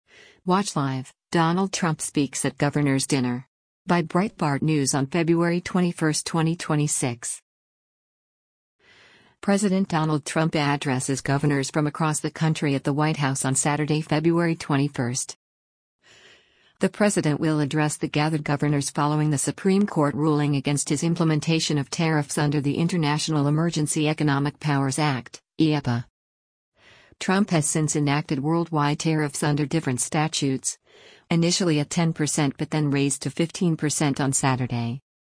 President Donald Trump addresses governors from across the country at the White House on Saturday, February 21.